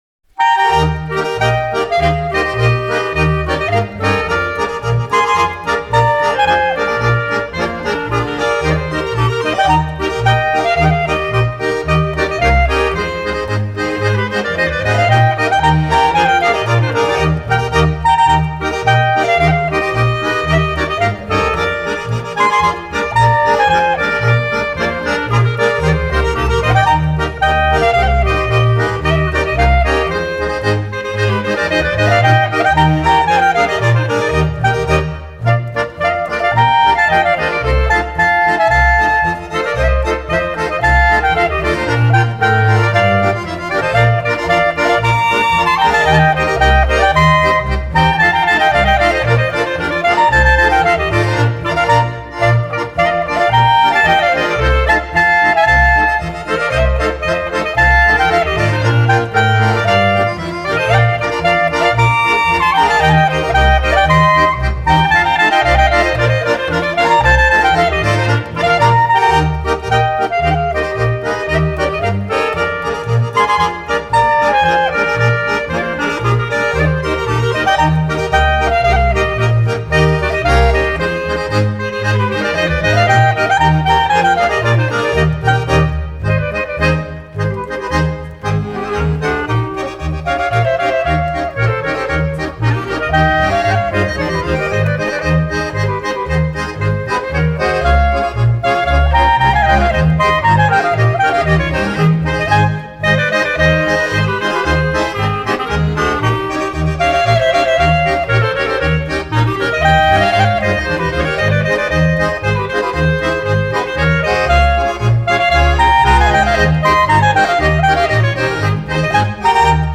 Chapella Erni, Scuol: Frohe Wanderer (Schottisch)
Chapella Erni, Scuol